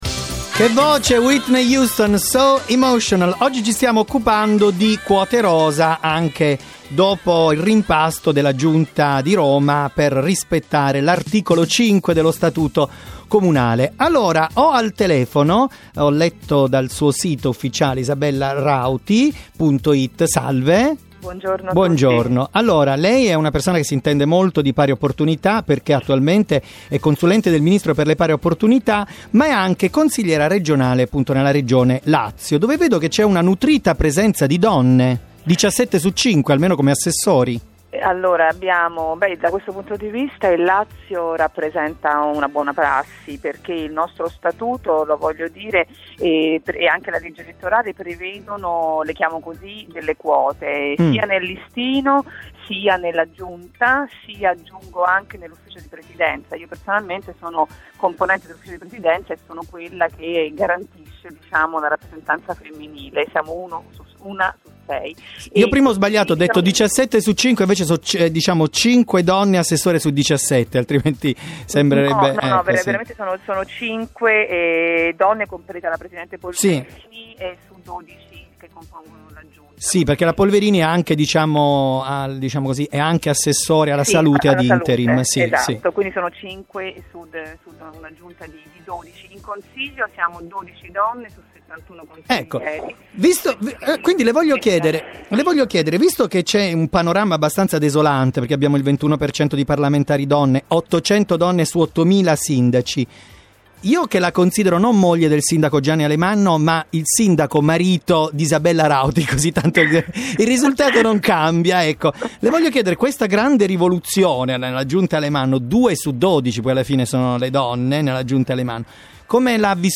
Radio 101 – Vladimir Luxuria intervista Isabella Rauti
Trasmissioni radio e TV